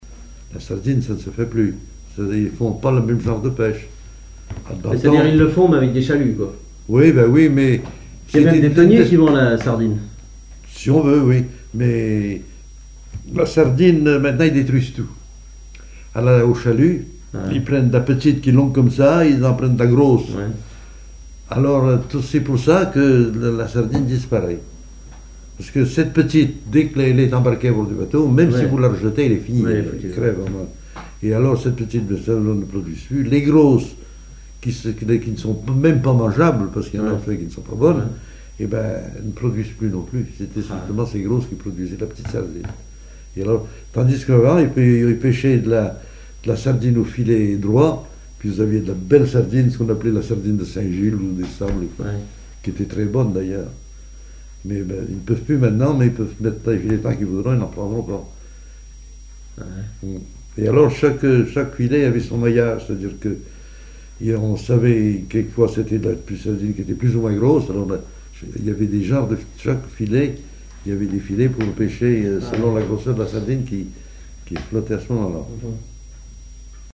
témoignages sur les activités maritimes locales
Catégorie Témoignage